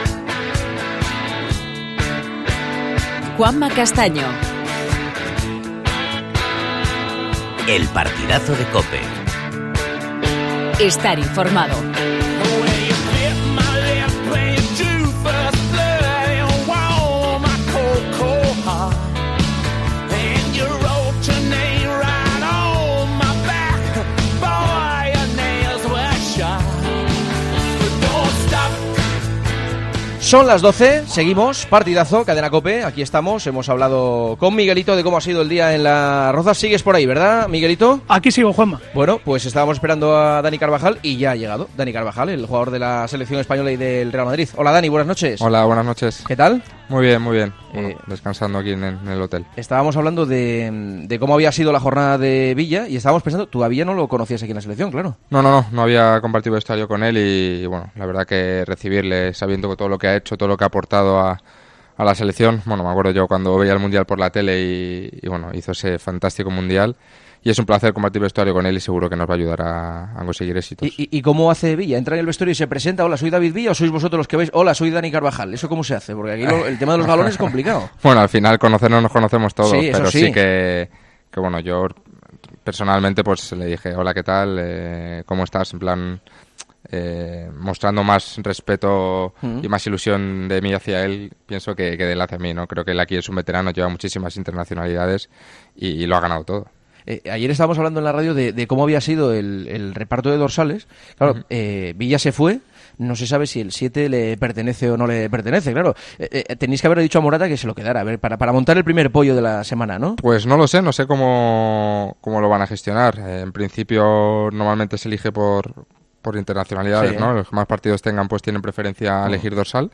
AUDIO: Dani Carvajal, futbolista del Real Madrid y de la selección española ha pasado por los micrófonos de El Partidazo de Cope, con Juanma Castaño...
Entrevistas en El Partidazo de COPE